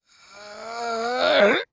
swordman_die1.wav